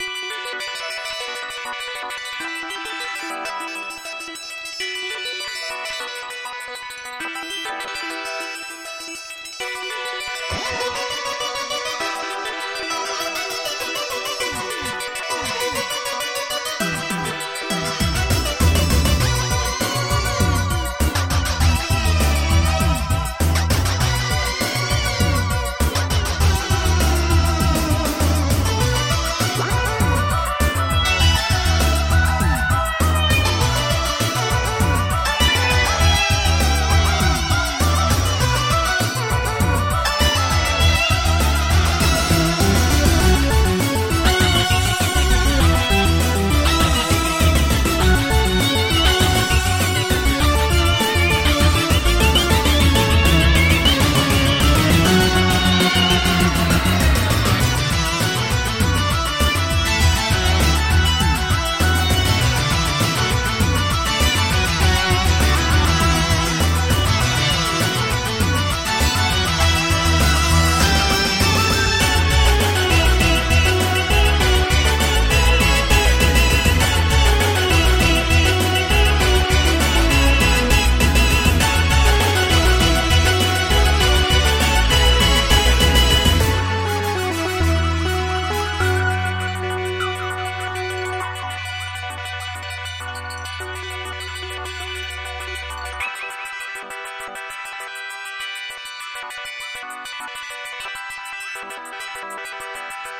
May be too messy even for a pretty messy level.